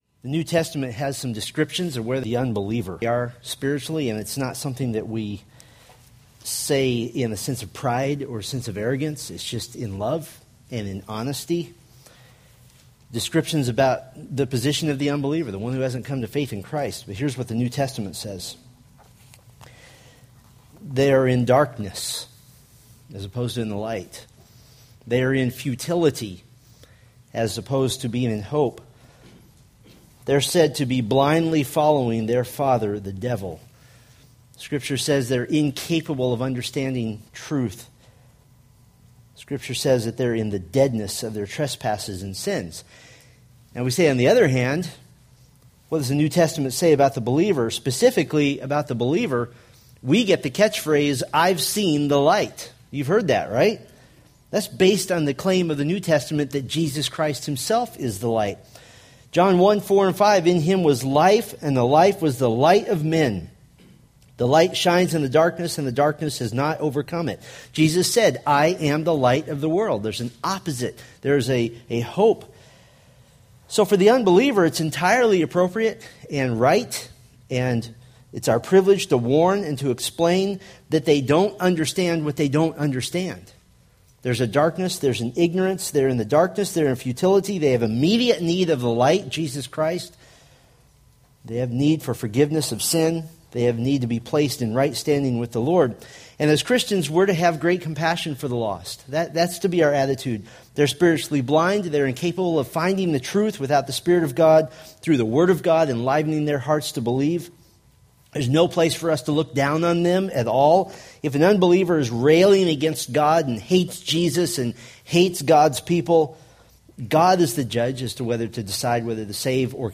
Preached April 3, 2016 from Isaiah 20:1-21:16